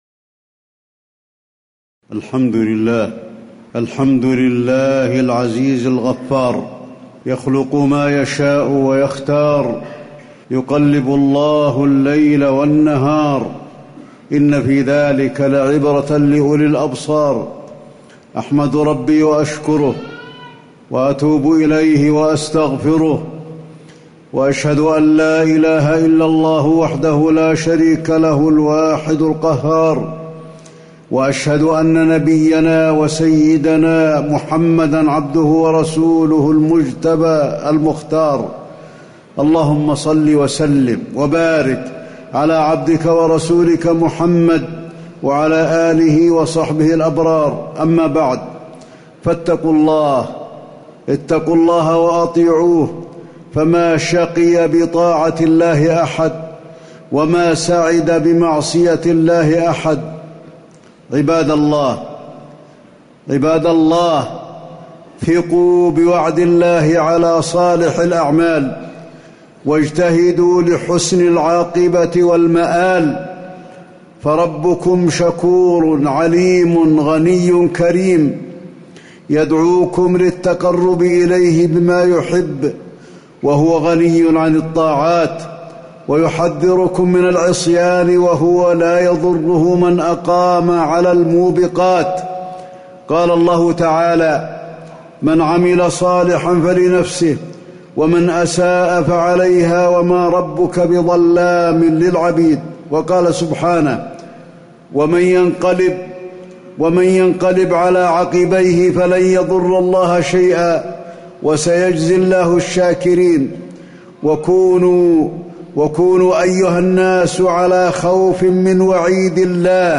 تاريخ النشر ٣٠ شعبان ١٤٣٨ هـ المكان: المسجد النبوي الشيخ: فضيلة الشيخ د. علي بن عبدالرحمن الحذيفي فضيلة الشيخ د. علي بن عبدالرحمن الحذيفي استقبال رمضان